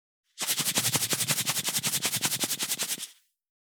382,ふりかけ,サラサラ,パラパラ,ジャラジャラ,
効果音厨房/台所/レストラン/kitchen
効果音